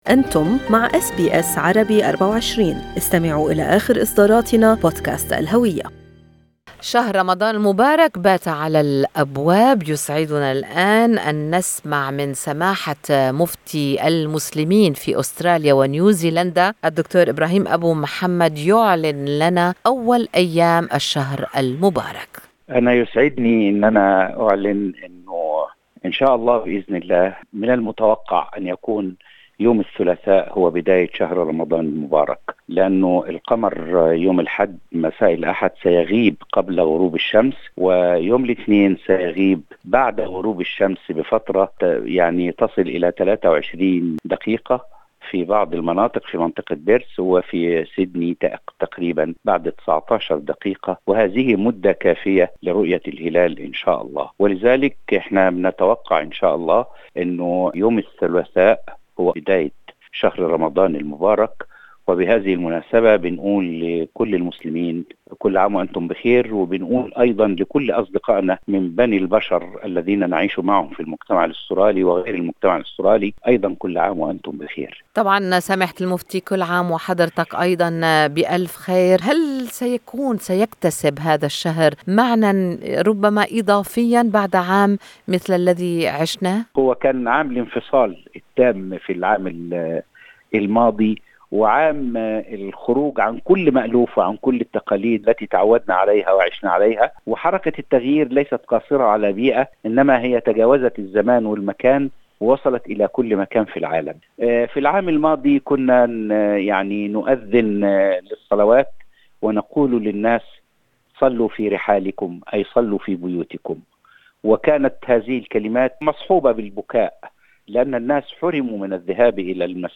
إستهلّ المفتي كلمته متمنيّا أن يكون شهرًا مليئًا بالخير على المسلمين وعلى كل الأصدقاء من بني البشر.